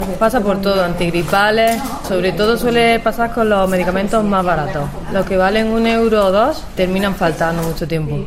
farmacéutica